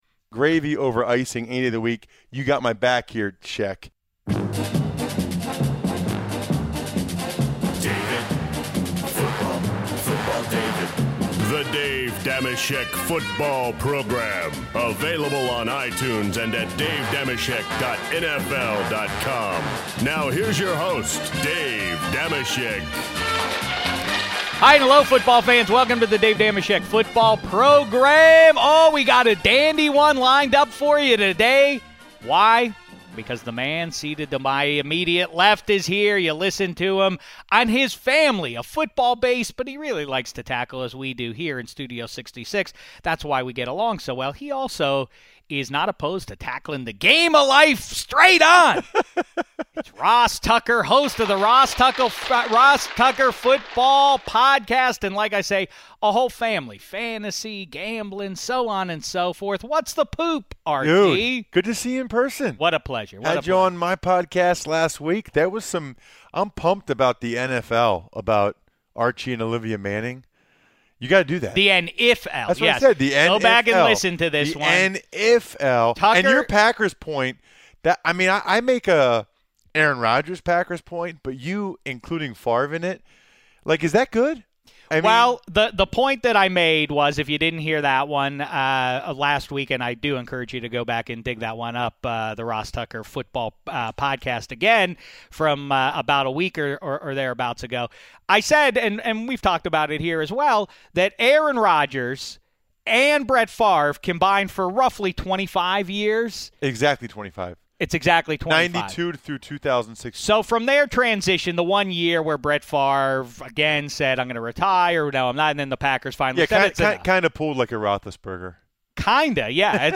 Also, in our new segment, "Let's Be Brief" the fellas attempt to speedily answer a series of football and "game of life"questions. Plus, the guys are joined by a surprise guest who happens to have a killer Ross Tucker impression.